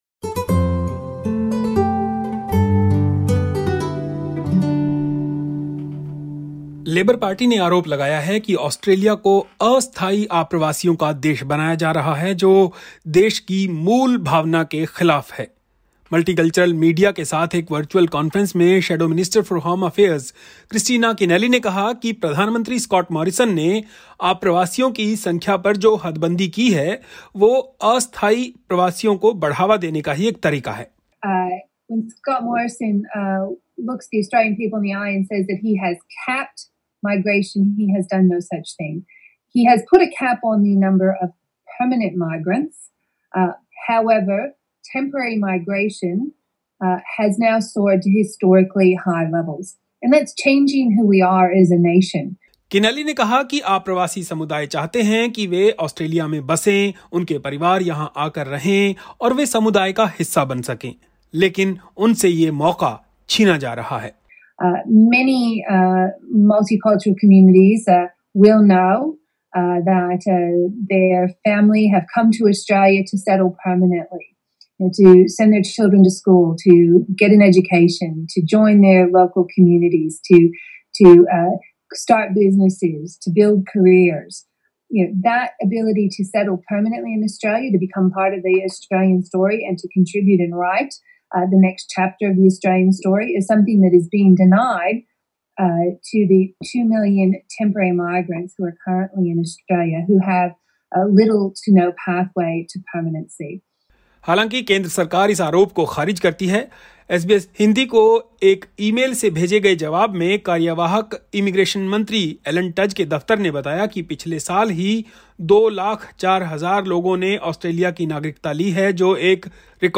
Shadow Minister for Home Affairs, Senator Kristina Keneally told multicultural media at a virtual press conference that the ability to settle permanently in Australia, to become part of the Australian story and to contribute in the next chapter of the story is something that has been denied to the millions of temporary migrants who are currently in Australia, who have little or no pathway to permanency.